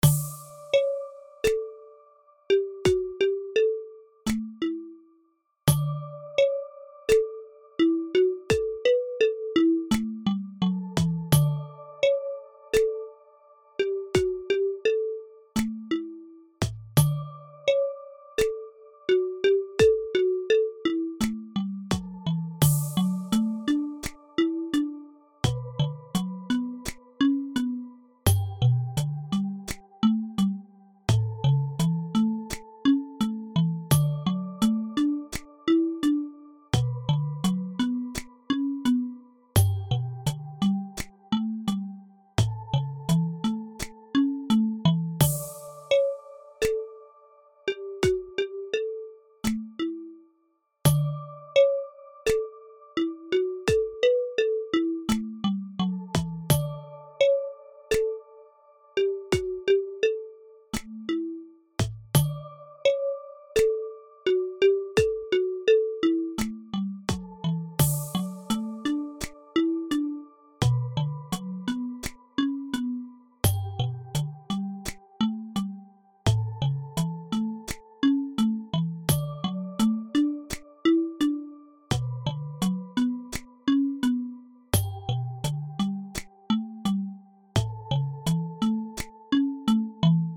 ゆったりしたBPMとシンプルな楽器構成。
のんびり感があります。